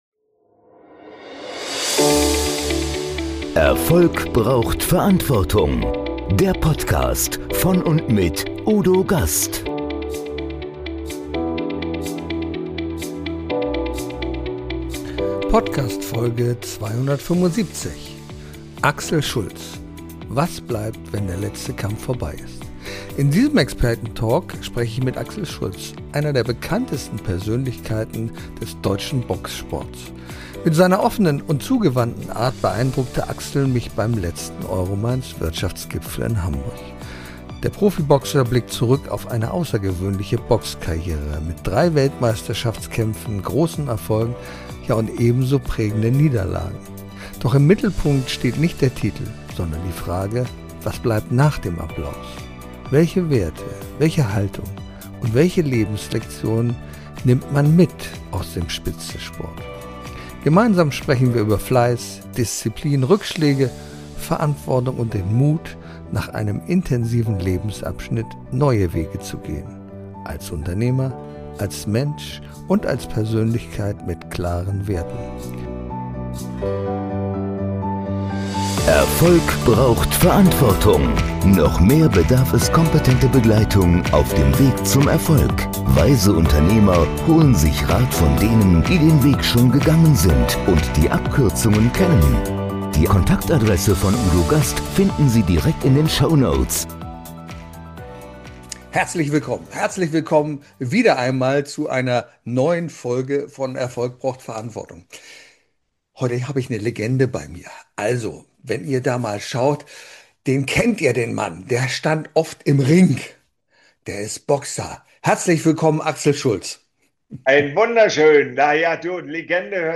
Beschreibung vor 3 Monaten In diesem Experten Talk spreche ich mit Axel Schulz, einer der bekanntesten Persönlichkeiten des deutschen Boxsports.